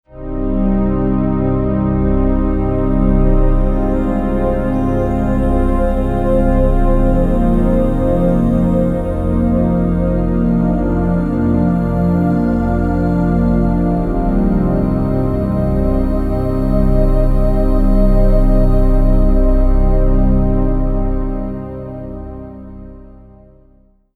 Piece featuring a couple of digital synth pads